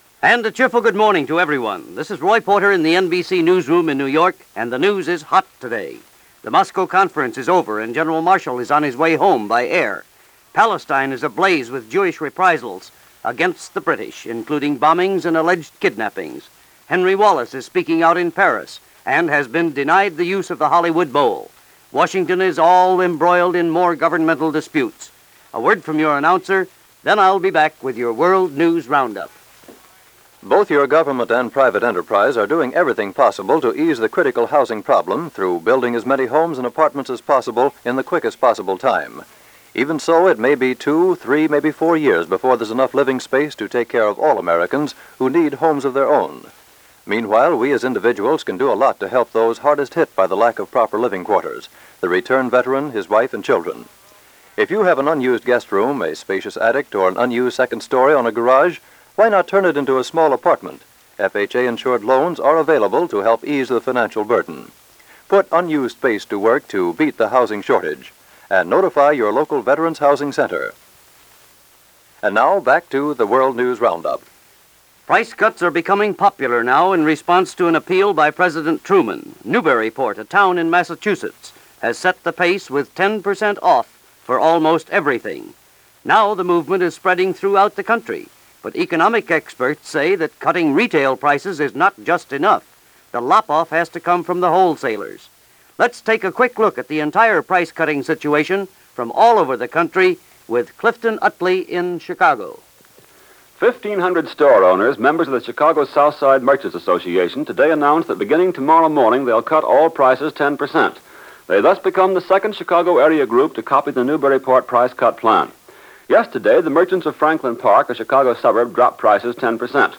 World News Roundup – NBC Radio – April 25, 1947 –